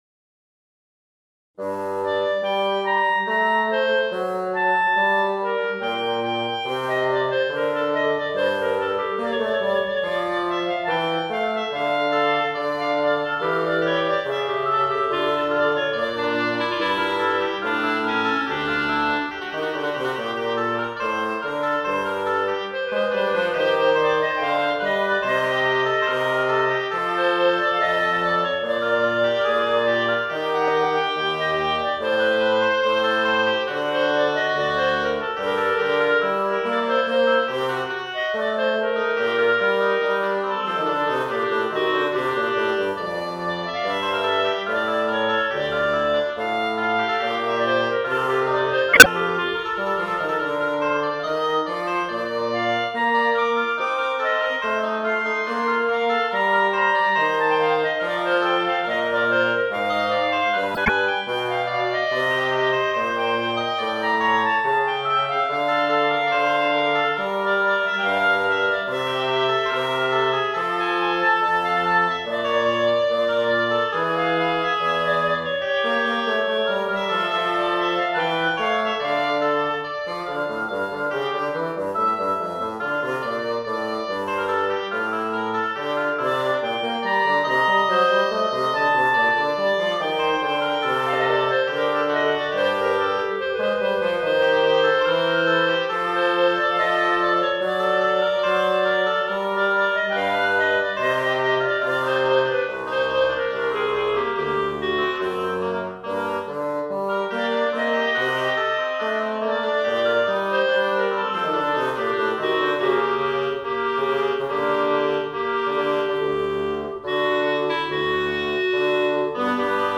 - Imitation en trois parties à trois voix ou instruments, avec un mélange d'une partie libre.
Probablement pour violon, alto et violoncelle, en si bémol majeur.
Précisation concernant le fichier midi : l'instrument choisi est le clavecin, car l'ordinateur ne rend pas bien le son des cordes.